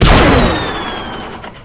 gun3.ogg